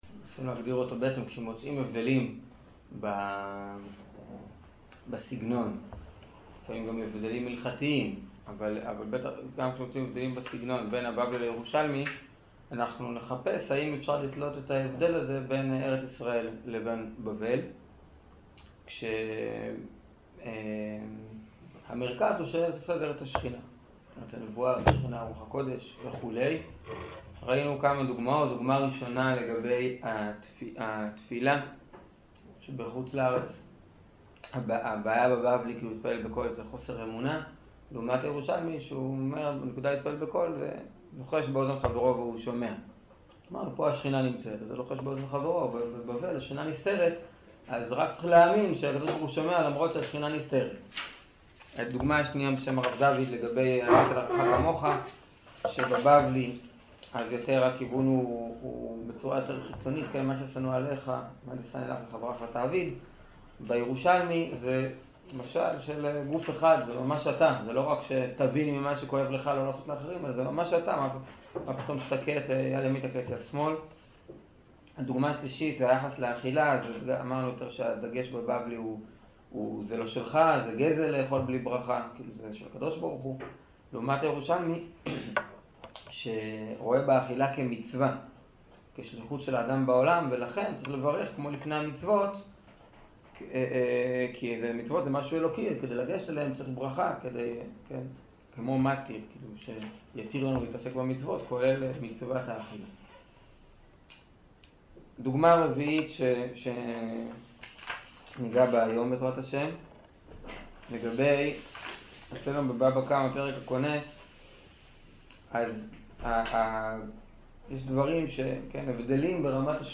שיעור דוגמאות להבדלים בין בבלי לירושלמי